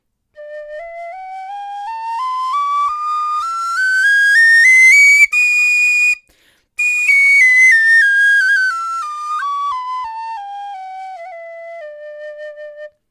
Sie hat einen angenehm weichen Klang und ist leicht über zwei Oktaven spielbar.
Stimmung D
Klangbeispiel Dixon Tinwhistle
dixon-tinwhistle-d-tonleiter.mp3